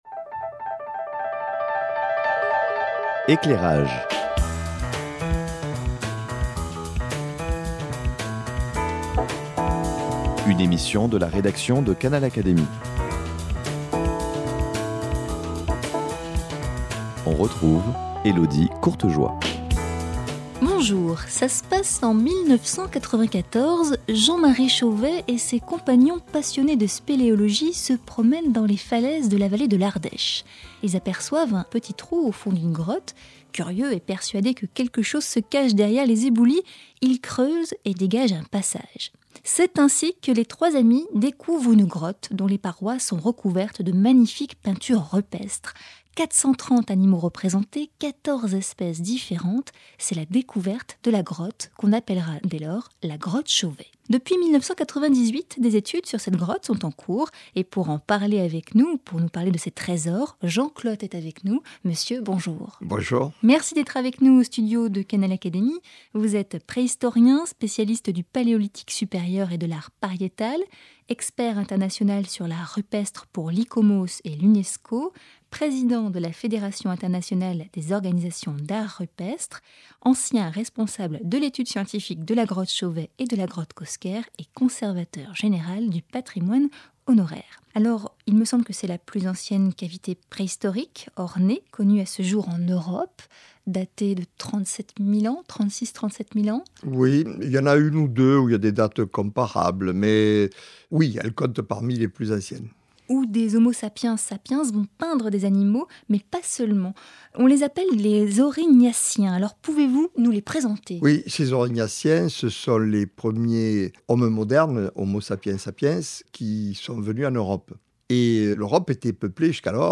Découverte en 1994 par hasard par une équipe de spéléologues, la grotte Chauvet est la plus ancienne cavité préhistorique ornée connue à ce jour en Europe (- 37 000 ans). Au cours de cette émission, Jean Clottes, spécialiste de l’art pariétal, nous livre ses interprétations sur le sens à donner de ces magnifiques peintures rupestres. Récit en compagnie d’un conteur aux accents du sud.